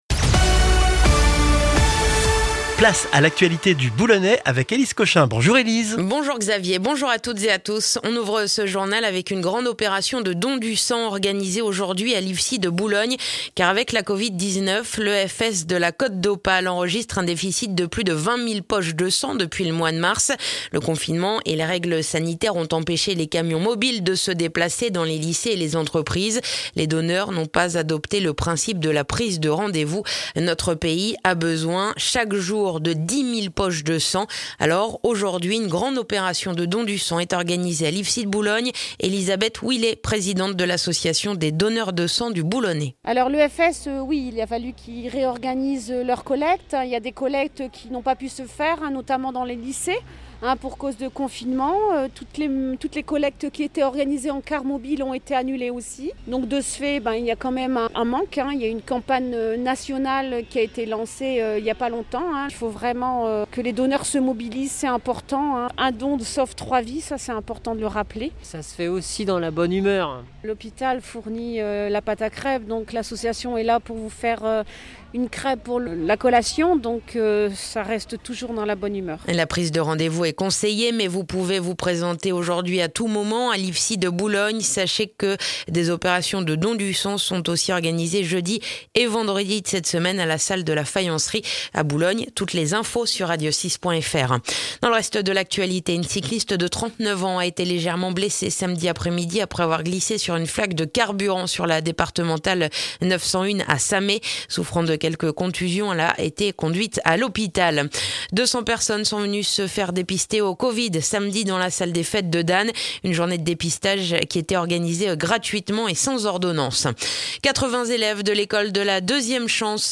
Le journal du lundi 21 septembre dans le boulonnais